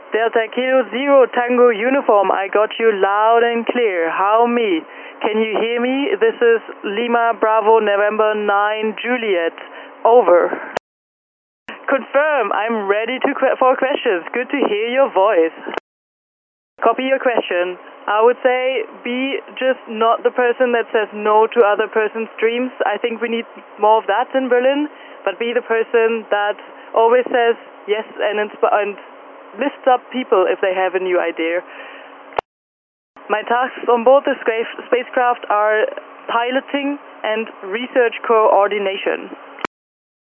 On April 1st 2025 at 07:33 UTC Rabea, using her personal callsign LB9NJ, had a scheduled contact with Technical University Berlin DK0TU. Due to some issues Rabea could only answer two questions from the students at DK0TU.